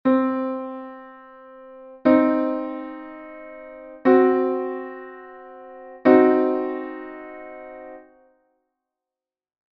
- 5ª Diminuída: formado por unha 3ª menor e unha 5ª Diminuída.
formacion_diminuido.mp3